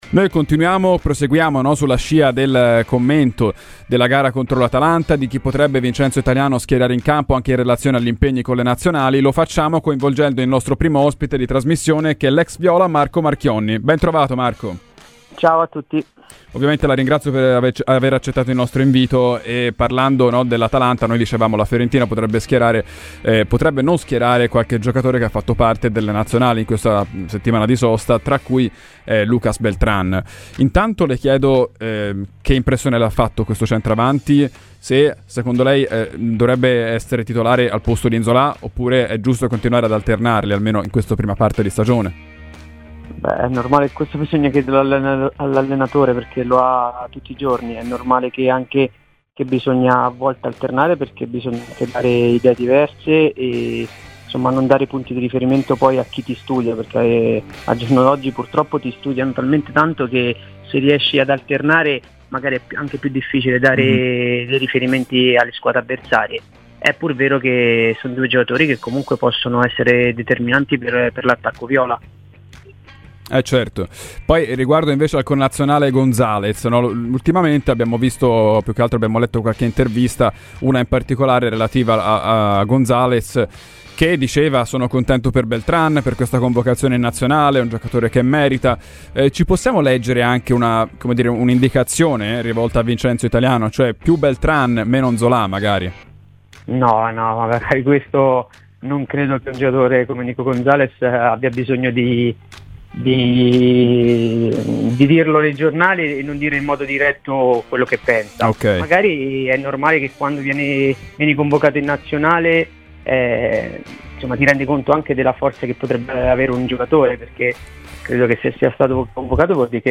L'ex calciatore della Fiorentina, Marco Marchionni, è intervenuto ai microfoni di Radio FirenzeViola.